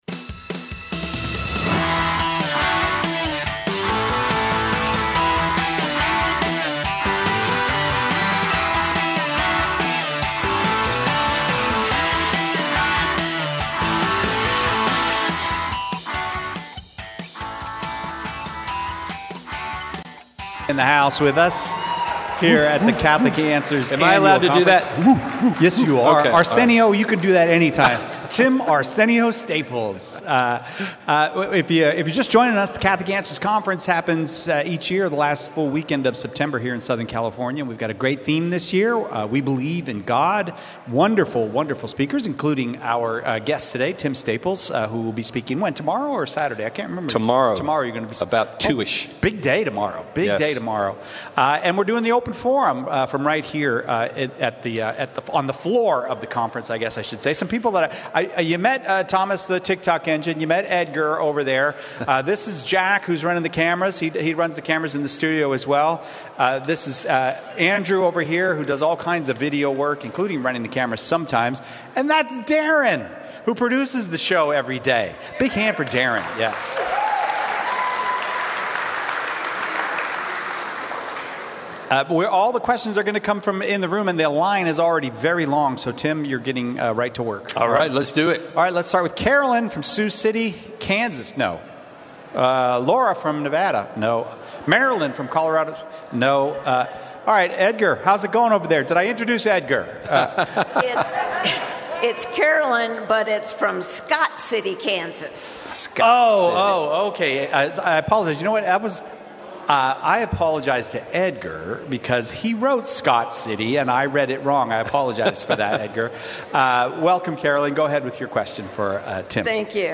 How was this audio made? Live from the Catholic Answers Conference